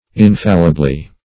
Search Result for " infallibly" : The Collaborative International Dictionary of English v.0.48: Infallibly \In*fal"li*bly\, adv.